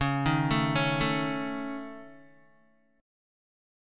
C#sus2#5 Chord